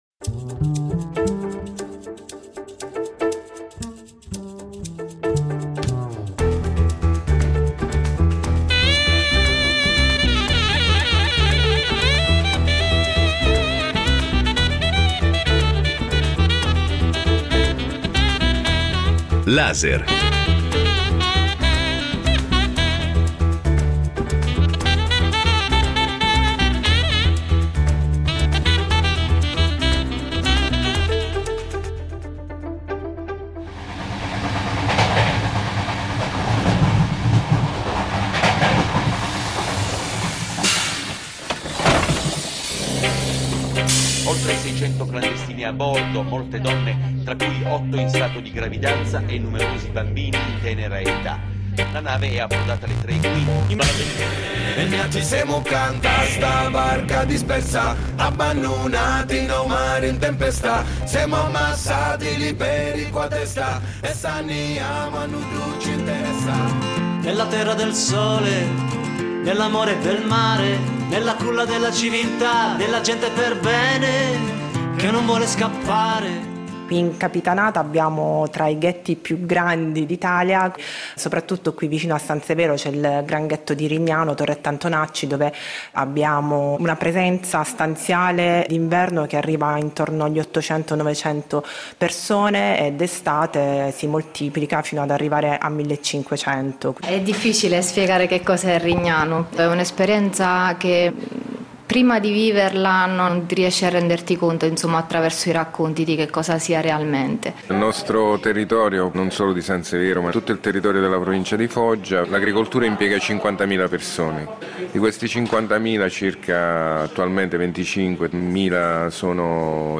Reportage nelle terre di Puglia